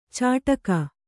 ♪ cāṭaka